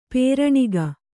♪ pēraṇiga